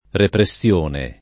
[ repre SSL1 ne ]